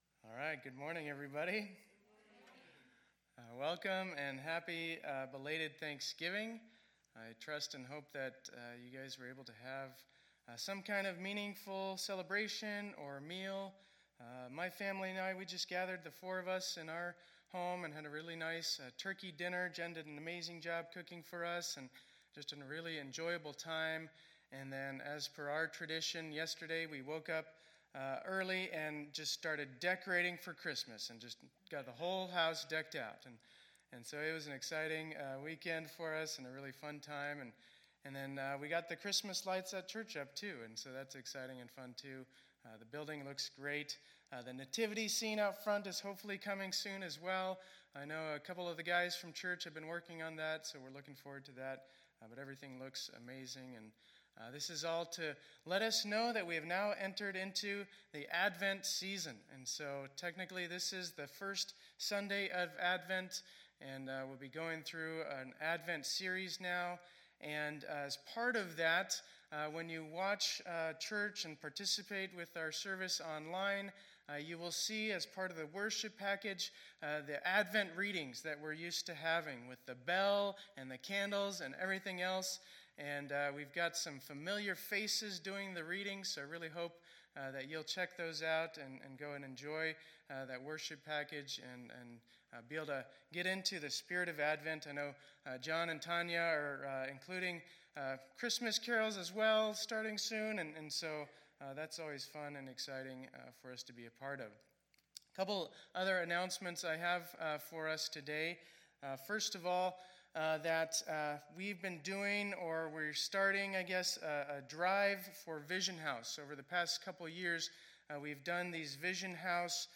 2020-11-29 Sunday Service